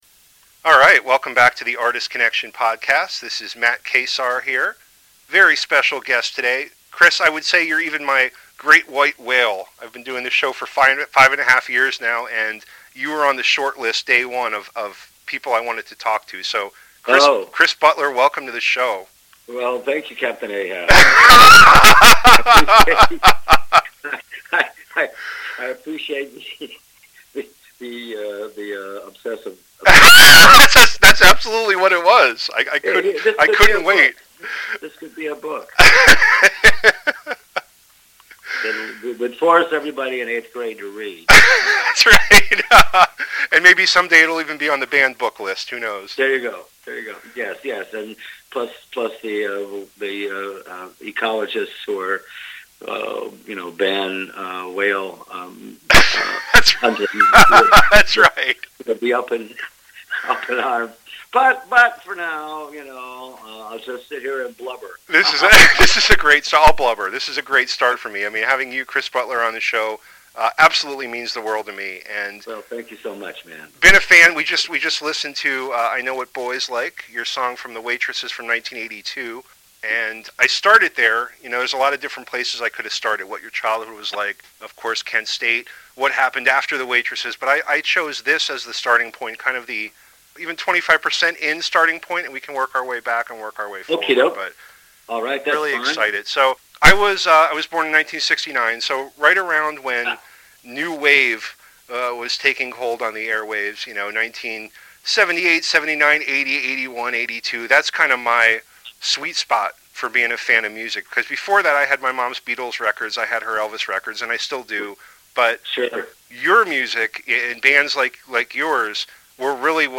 Today’s guest is musician Chris Butler.
Music from various eras of his career are mixed in throughout the conversation.